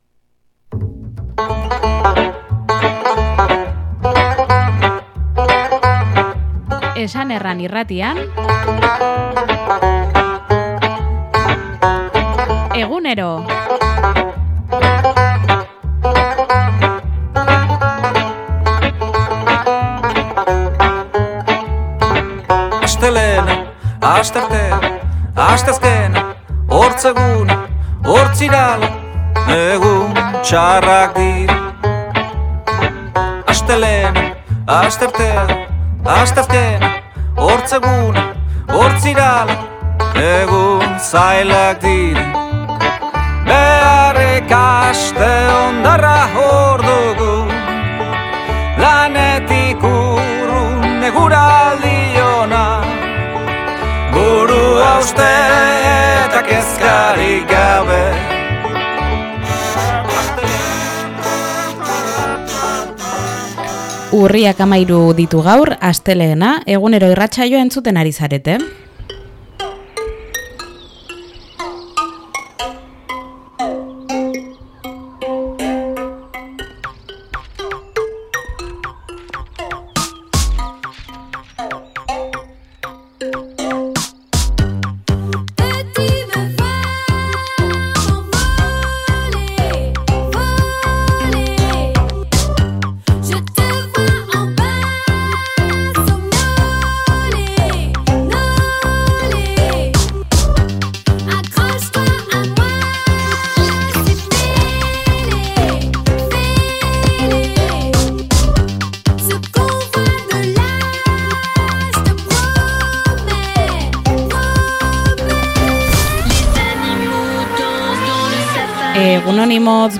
eskualdeko magazina